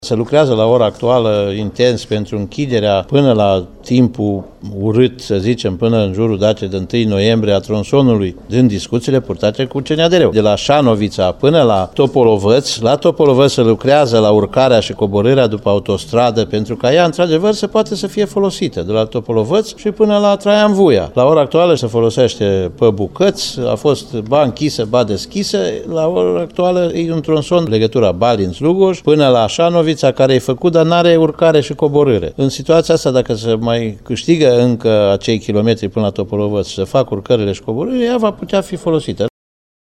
Titu Bojin precizează că se lucrează la urcarea pe autostrada la Topolovăţu Mare.